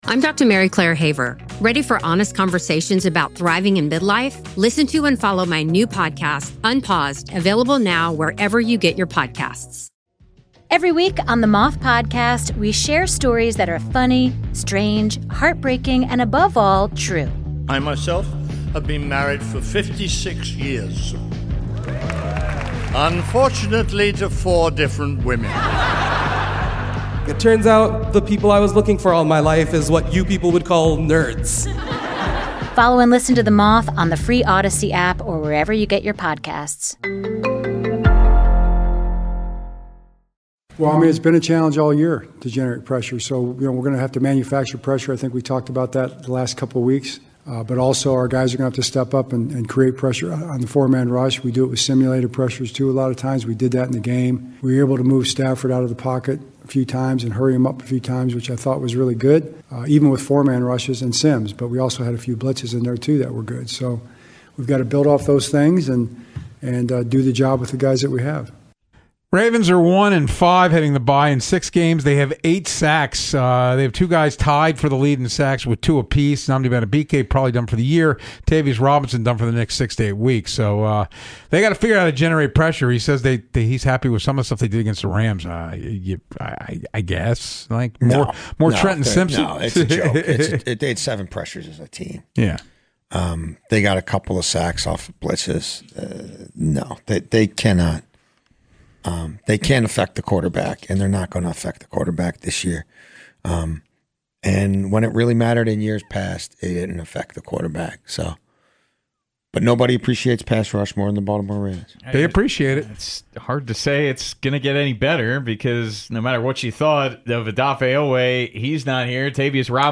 The guys listen to sound from John Harbaugh and discuss his comments.